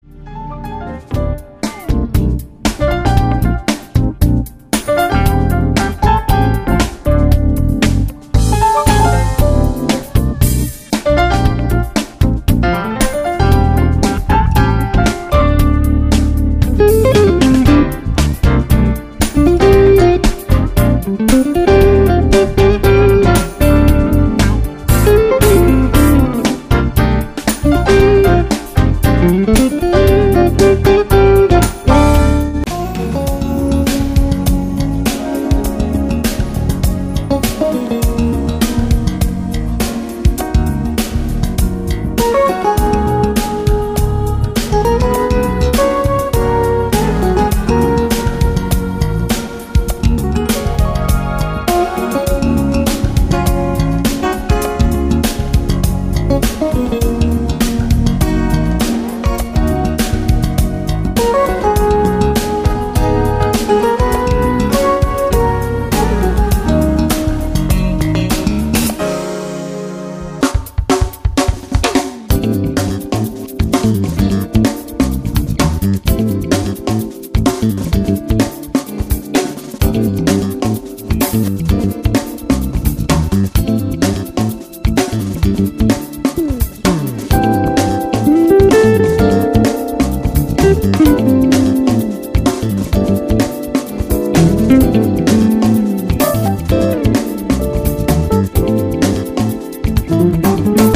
contemporary jazz quartet